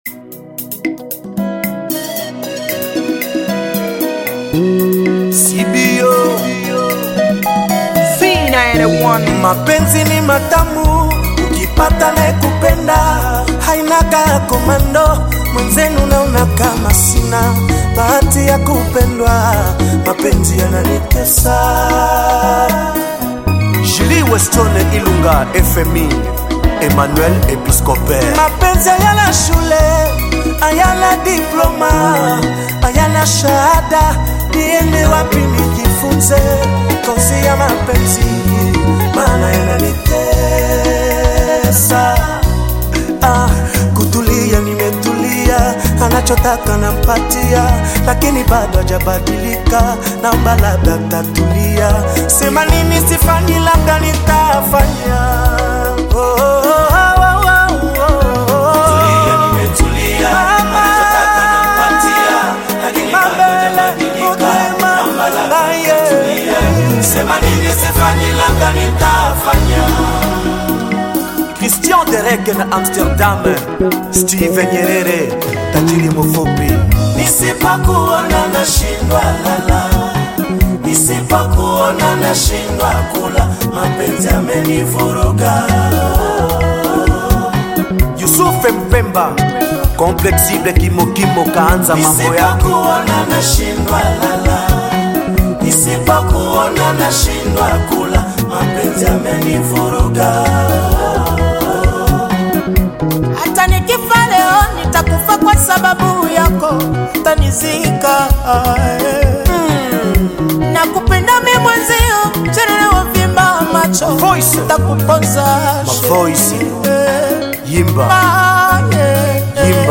Bongo Flava
heartfelt and melodious song
soulful vocals
African Music